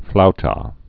(floutä)